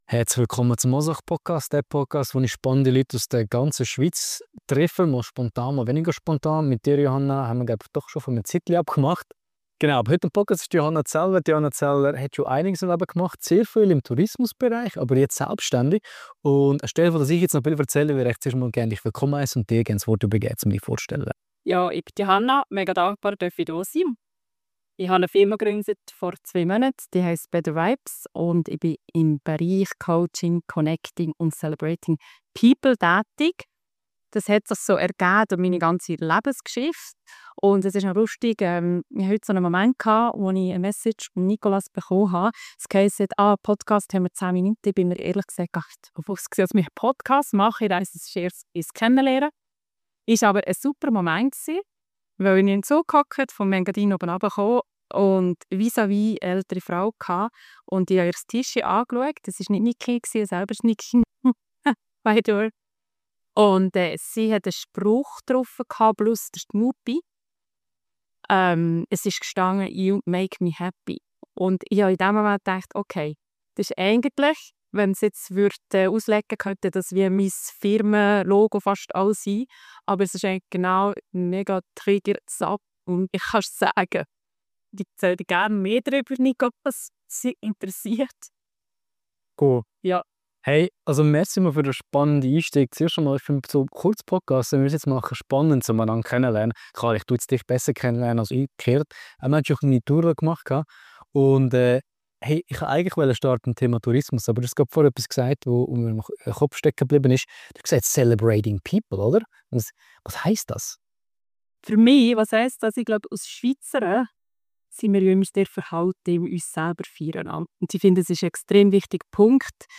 Ein inspirierendes Gespräch über Balance, Achtsamkeit und den Mut, neu zu starten.